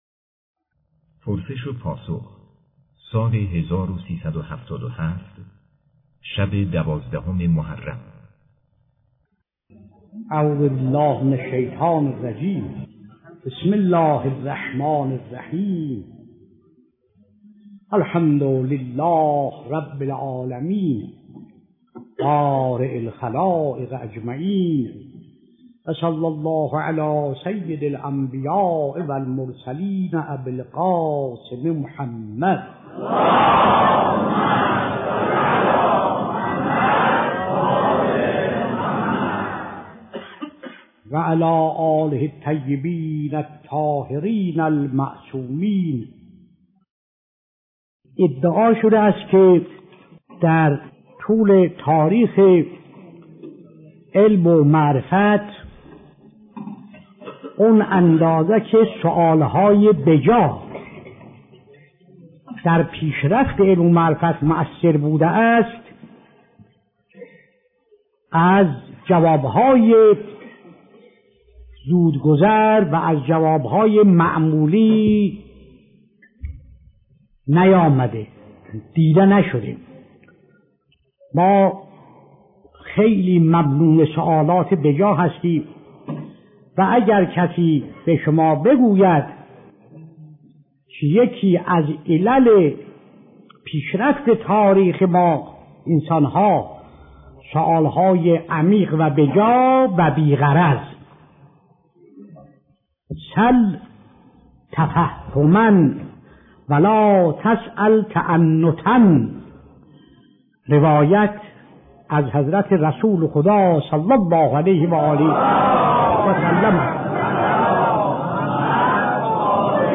سلسله جلسات پرسش و پاسخ - 7